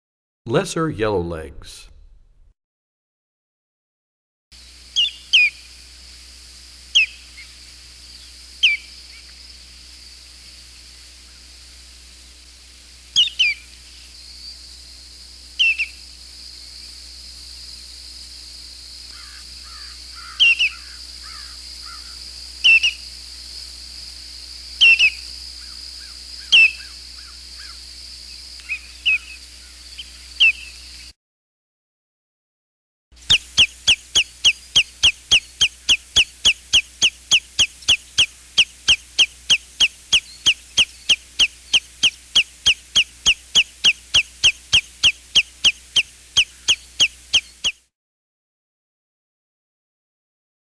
53 Lesser Yellowlegs.mp3